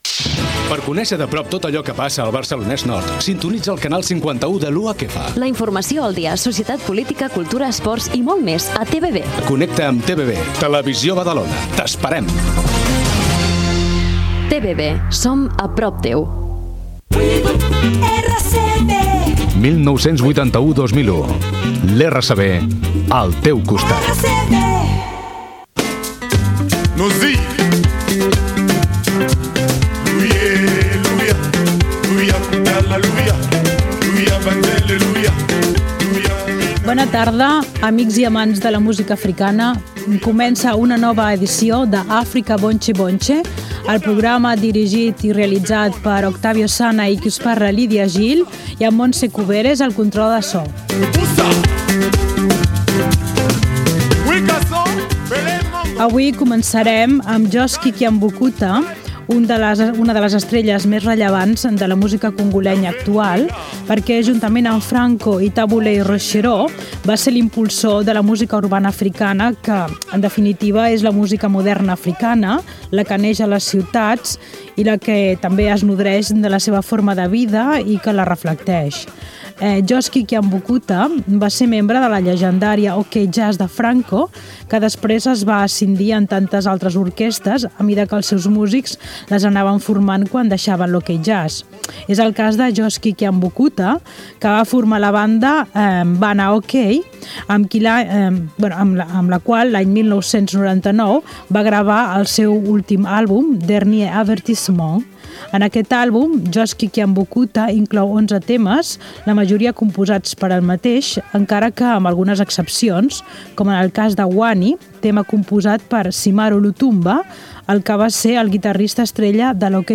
Antunci de TV Badalona,indicatiu dels 20 anys de RCB, presentació del programa, temes musicals, publicitat, tema musical
Musical